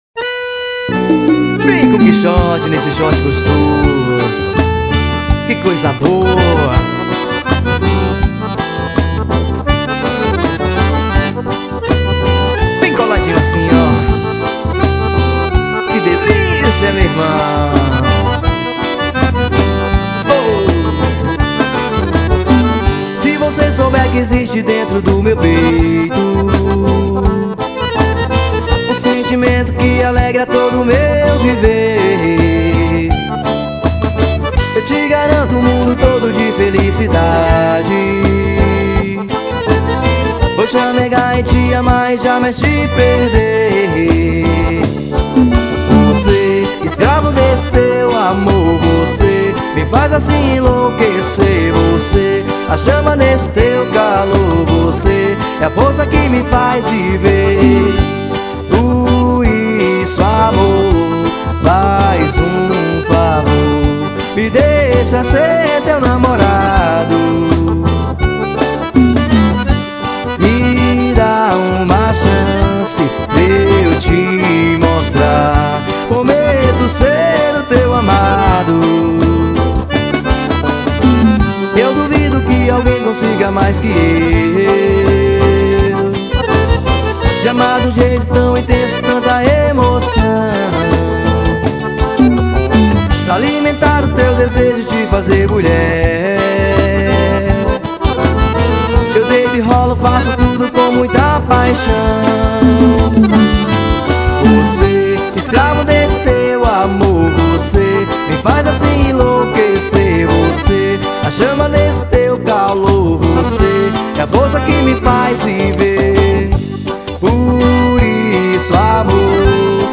xote baião.